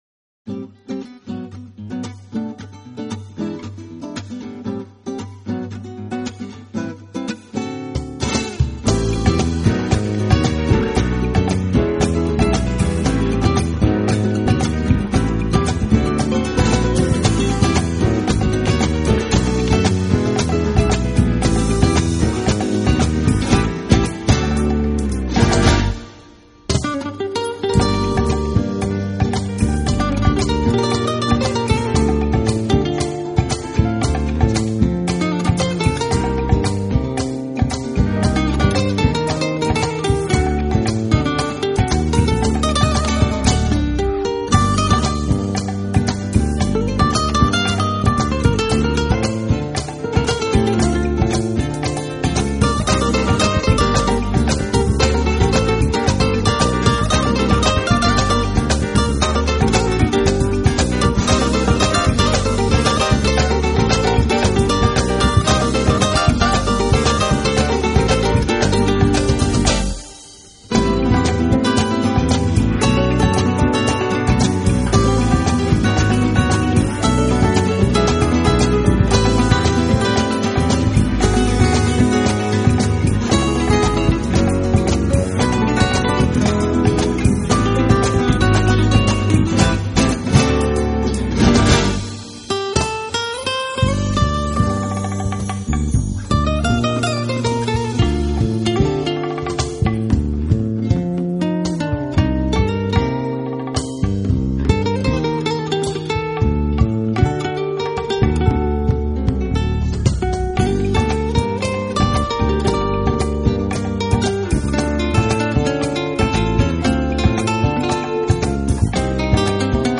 弦的振动、琴声的木质共鸣、空间感的残响，体现了西班牙吉他的极高品质，充分演绎最
激情奔放的弗拉明戈曲韵，被演绎的既野性，又高贵浪漫，西班牙吉它领略绚丽的异域风